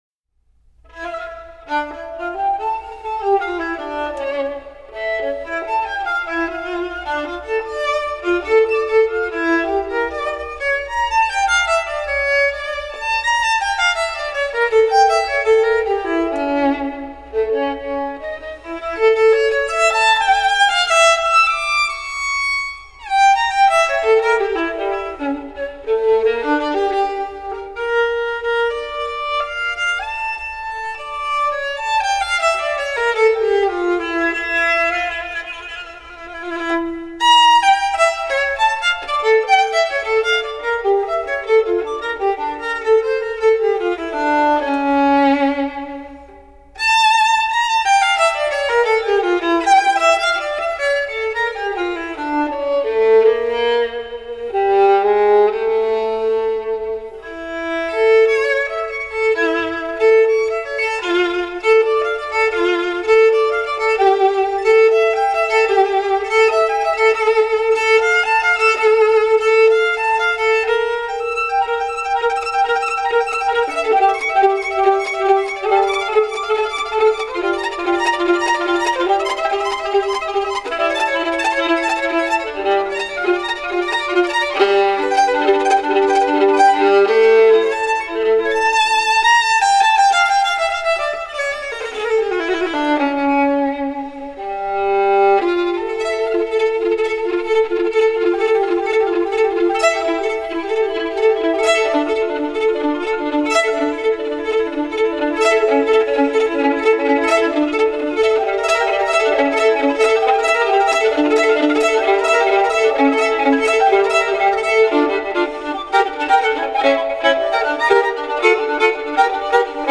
Música latina